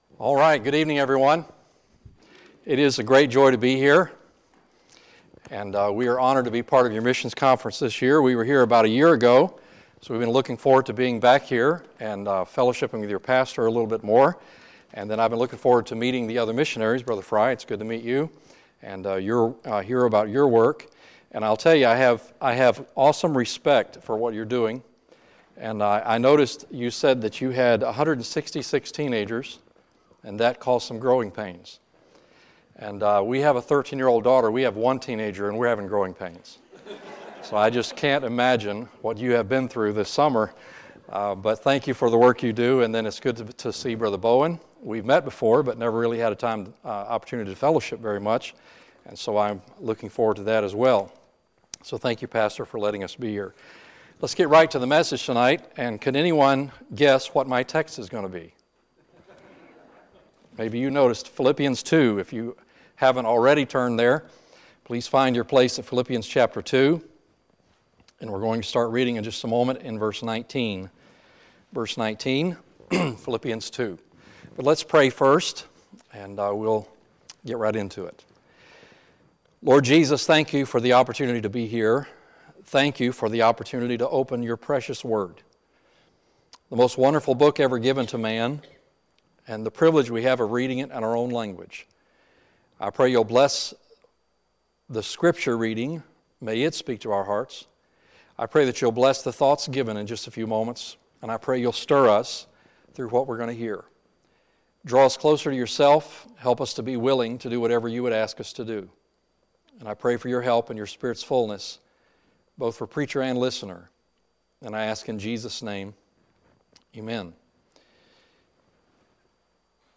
Tuesday, September 25, 2018 – Tuesday Evening Service